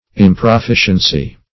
Search Result for " improficiency" : The Collaborative International Dictionary of English v.0.48: Improficience \Im`pro*fi"cience\, Improficiency \Im`pro*fi"cien*cy\, n. Lack of proficiency.